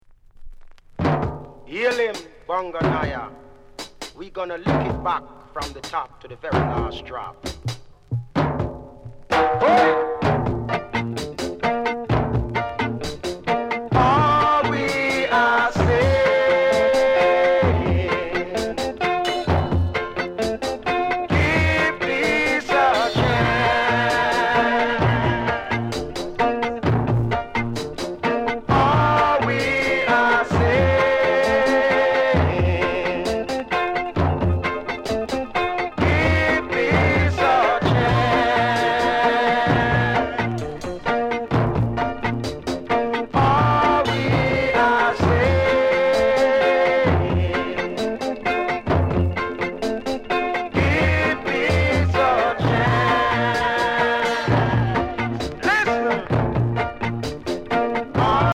EARLY REGGAE